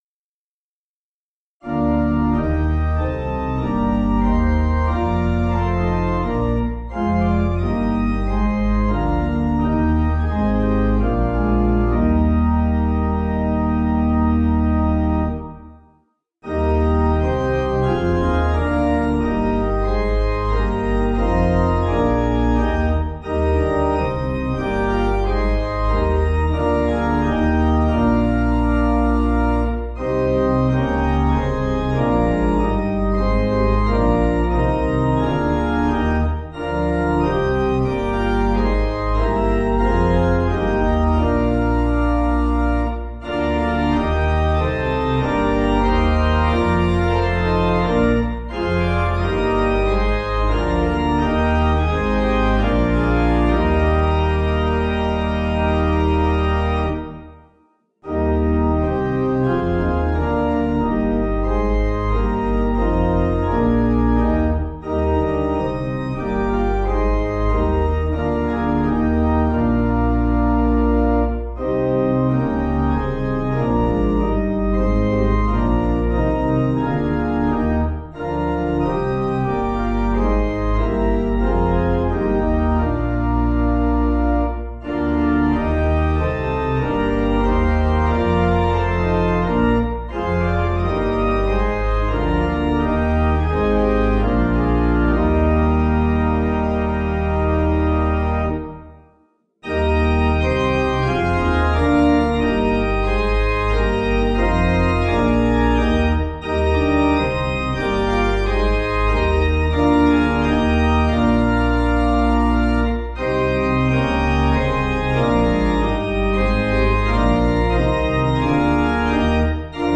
(CM)   3/Eb 496kb